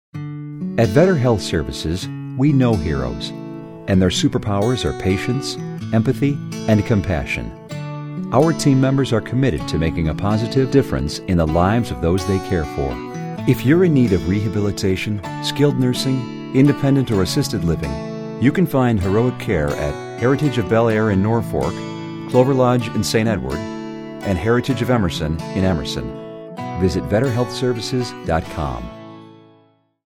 VSL Heroes Radio Spot